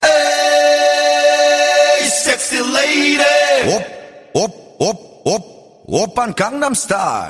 Акапельно